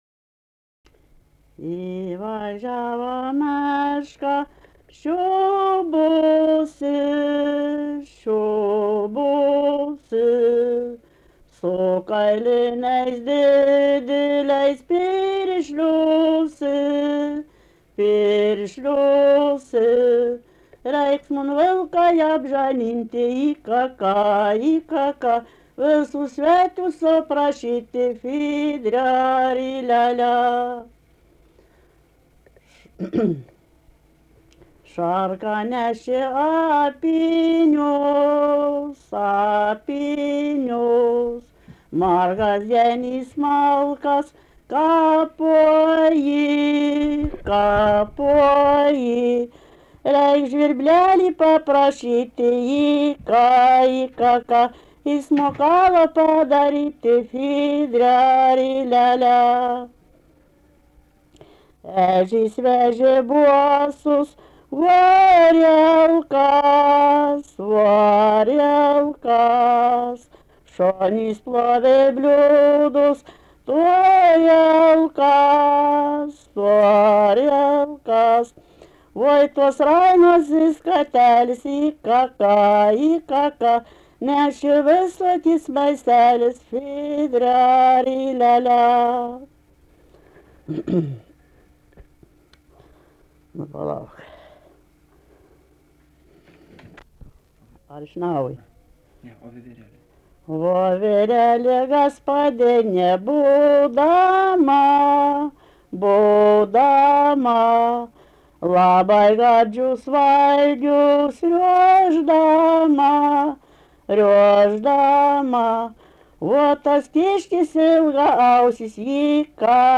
daina
Genaičiai
vokalinis